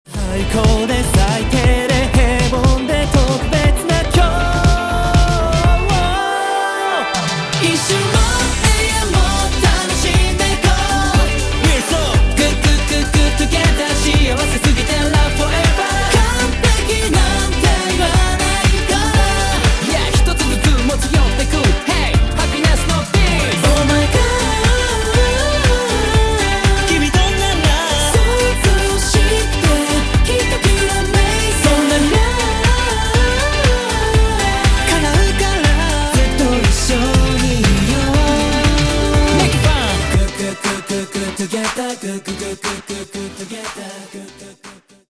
ポップスを軸に様々なジャンルを盛り込んだ